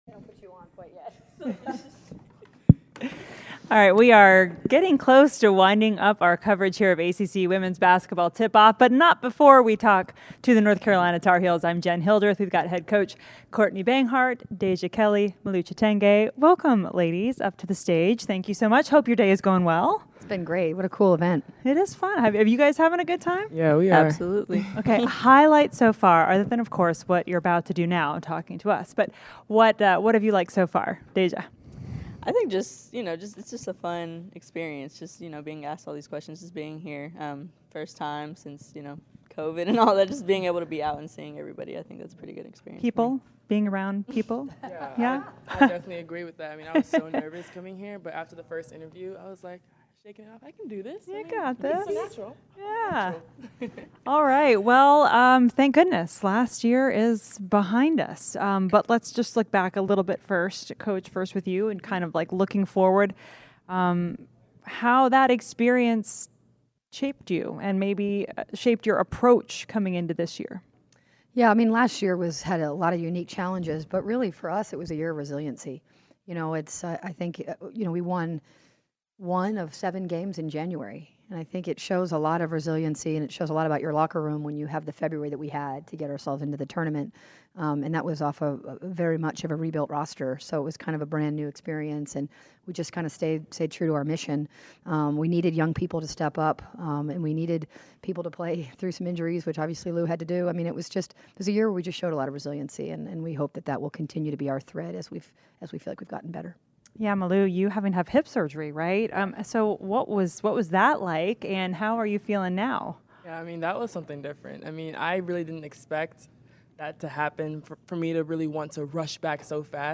The second day of the ACC Tip-Off event in Charlotte featured representatives from each women’s basketball program in the conference.